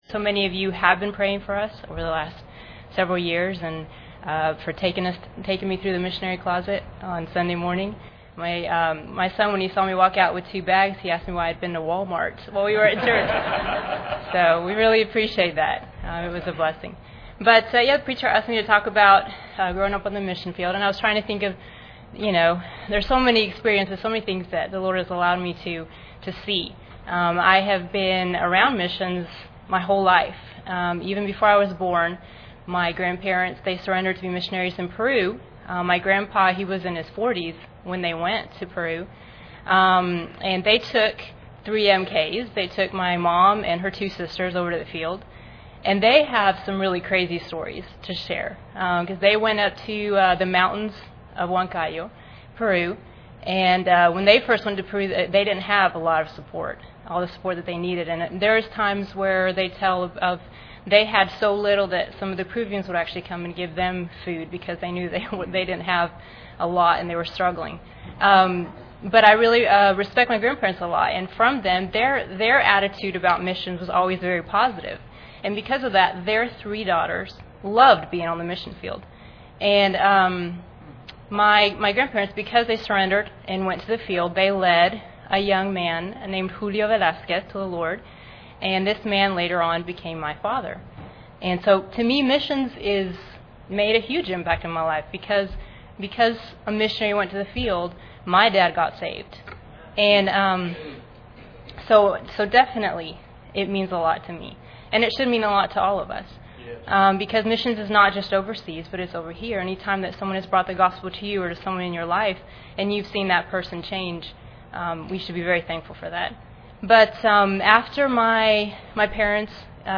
Series: 2011 Missions Conference
Service Type: Special Service